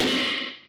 Crashes & Cymbals
Crash.wav